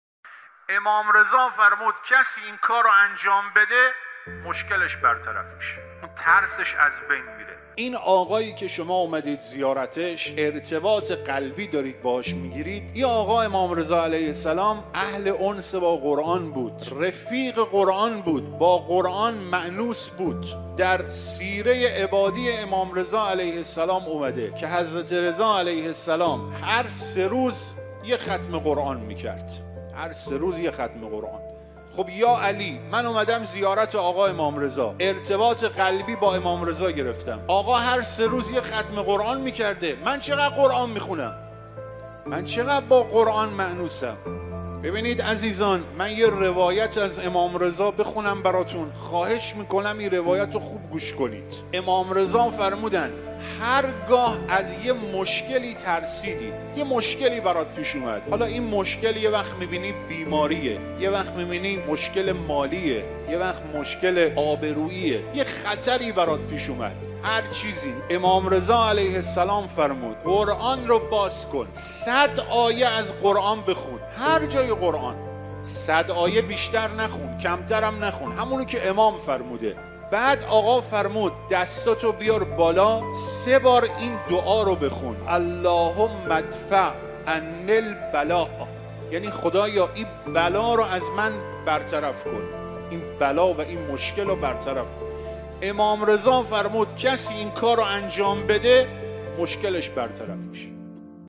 فرازی از سخنرانی